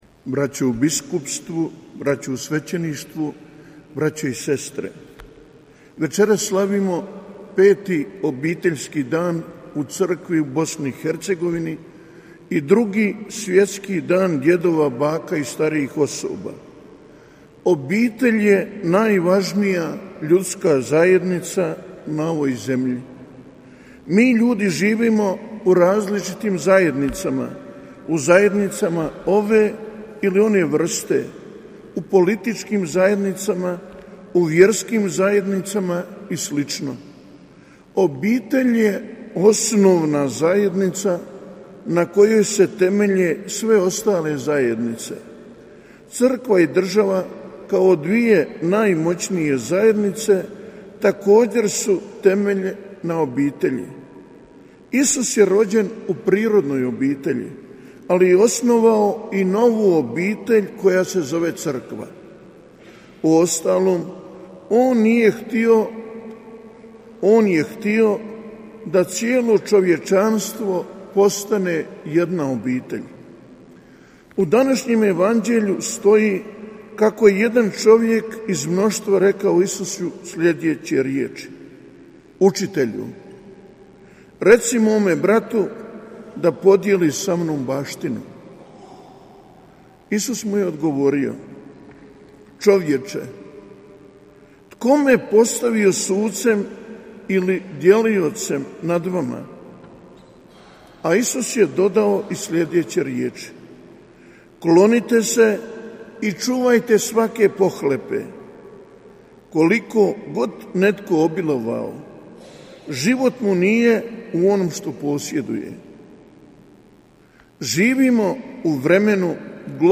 Svetu misu uoči Petog Obiteljskog dana u Bosni i Hercegovini i Drugog Svjetskog dan djedova, baka i starijih osoba u večernjim satima u subotu, 30. srpnja 2022., u župnoj crkvi Svete Obitelji u Kupresu predvodio je predsjednik Vijeća za obitelj Biskupske konferencije Bosne i Hercegovine mons. Marko Semren, pomoćni biskup banjolučki. Prigodnu propovijed biskupa Semrena prenosimo u cijelosti: